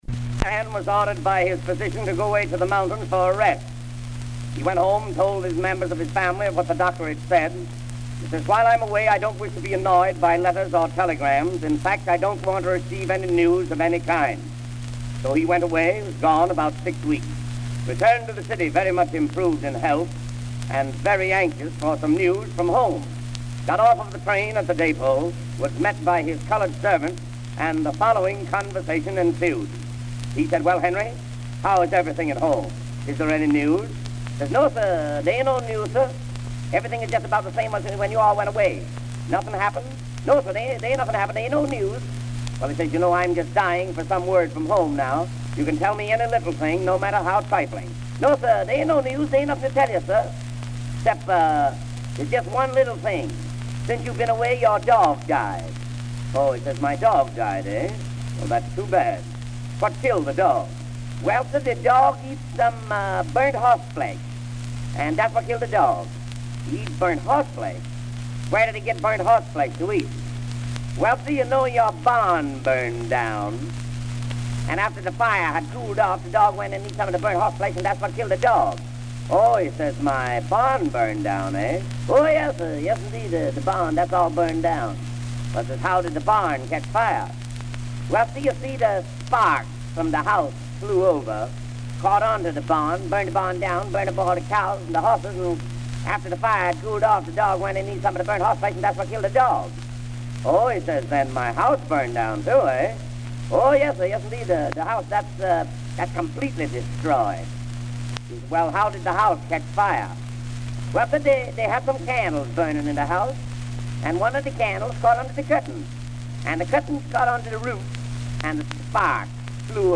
Comedy monologue about a man returning home and learning that his dog died while he was away.